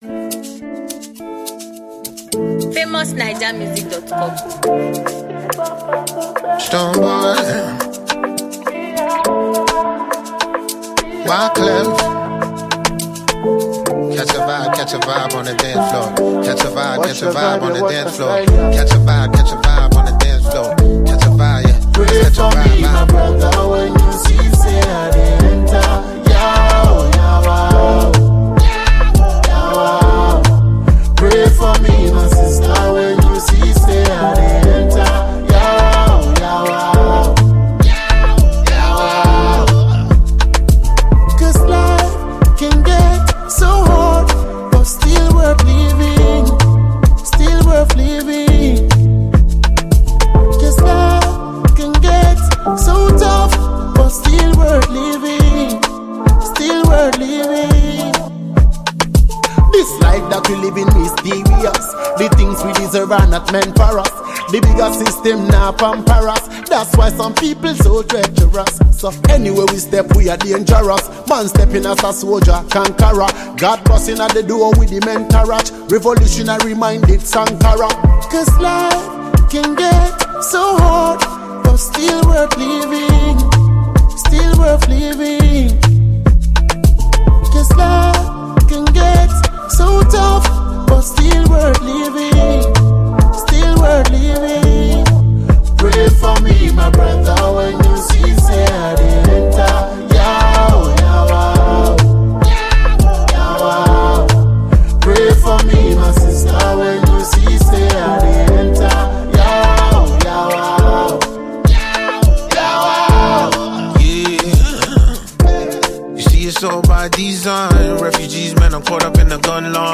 contributes a smooth and flawless vocal exhibition.